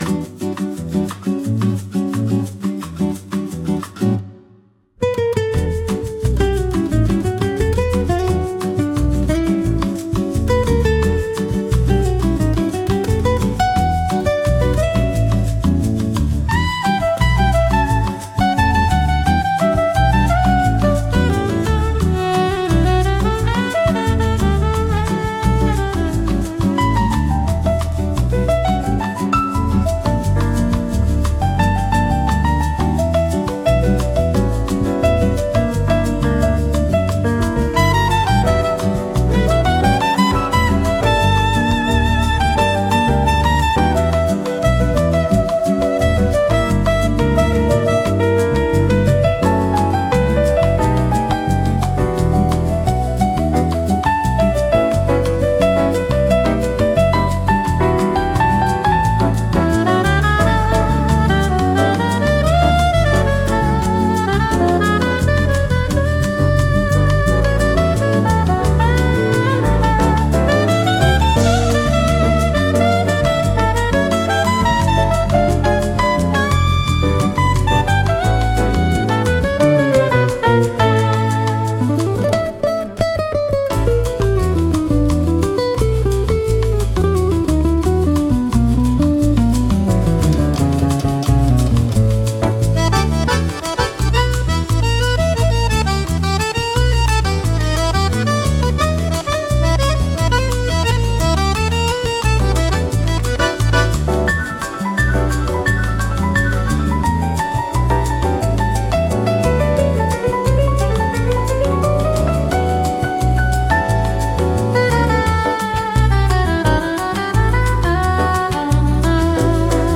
INSTRUMENTAL 6